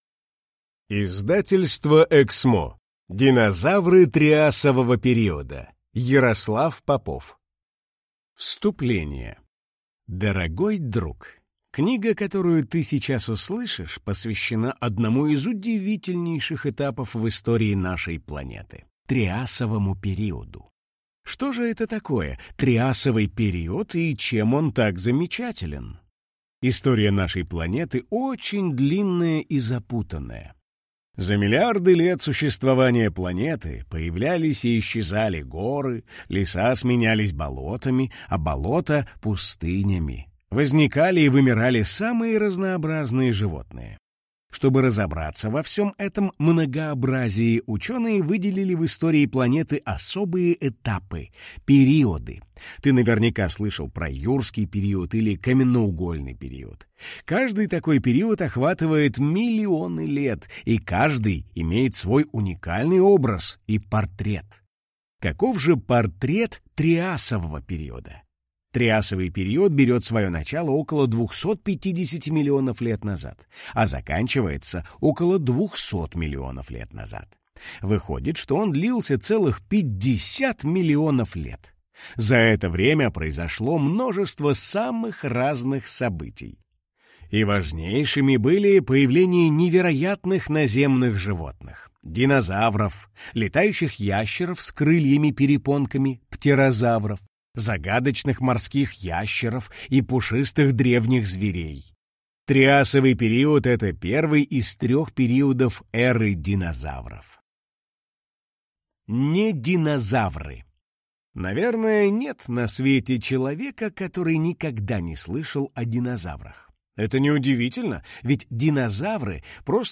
Аудиокнига Динозавры триасового периода | Библиотека аудиокниг